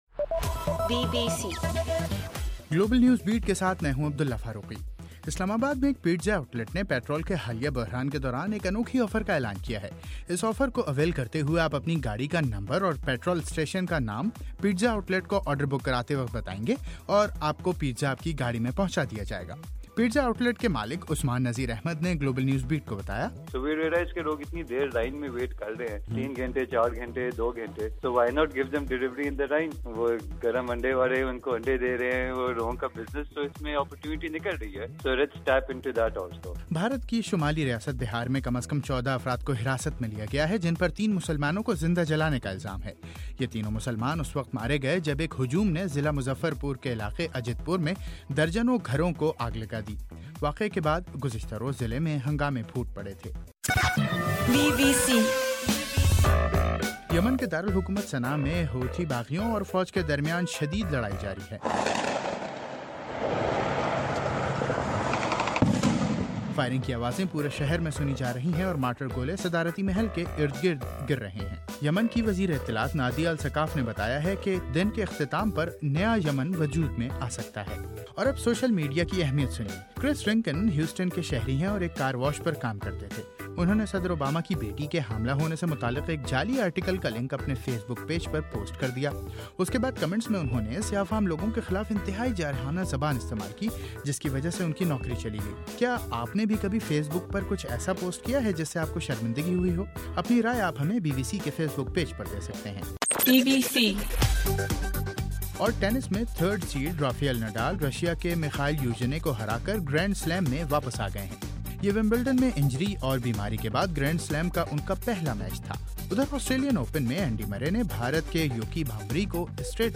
جنوری 19: رات 9 بجے کا گلوبل نیوز بیٹ بُلیٹن